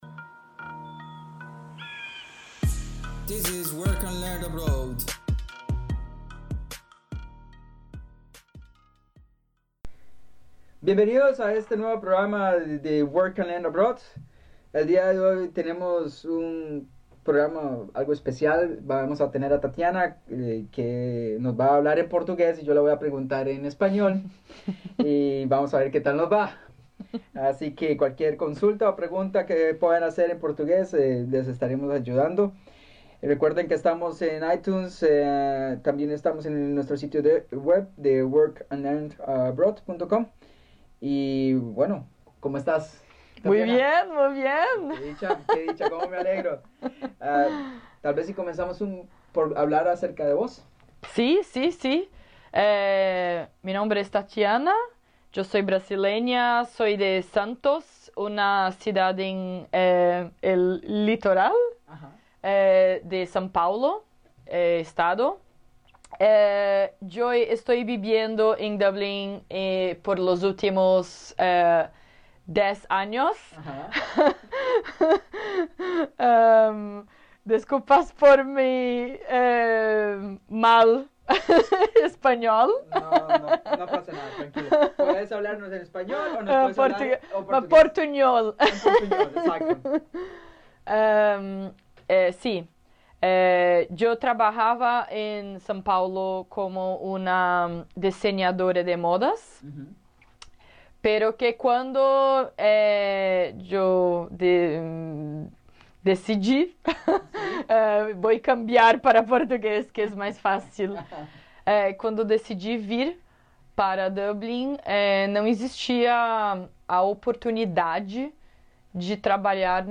第34 Trabalho no exterior Entrevista